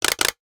NOTIFICATION_Click_11_mono.wav